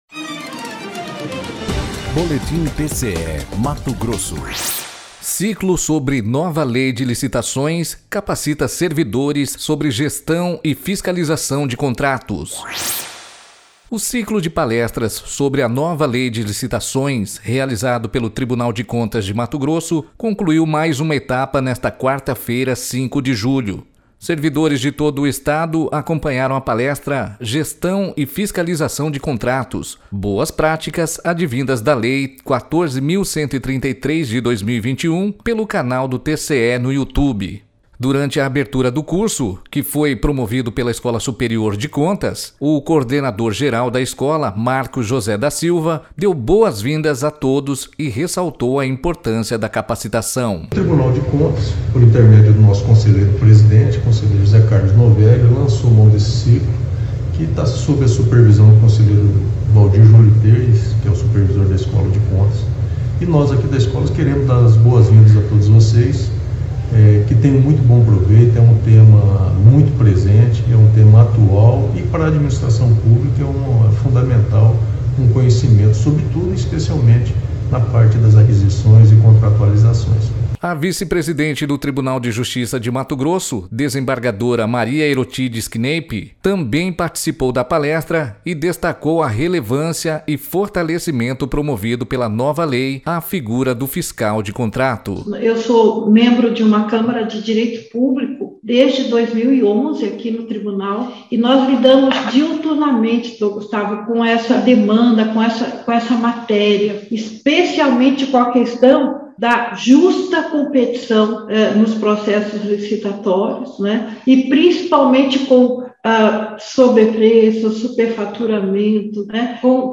Sonora: Maria Erotides Kneip - vice-presidente do TJ-MT